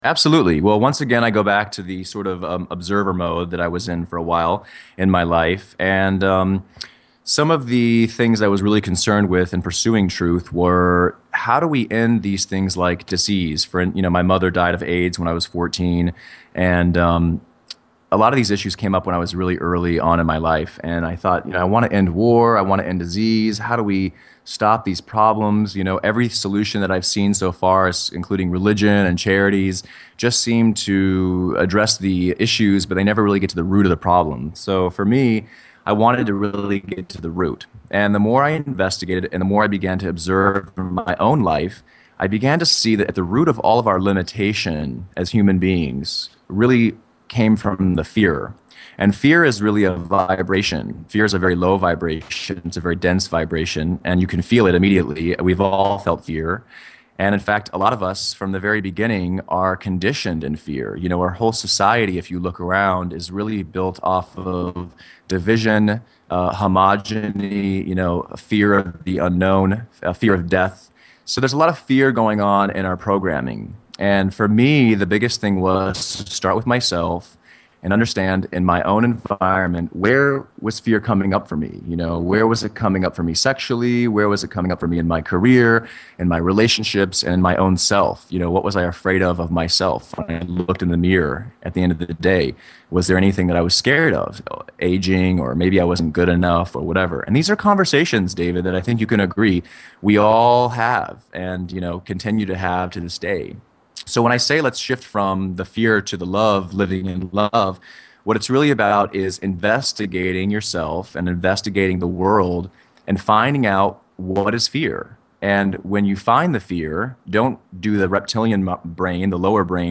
The Interview: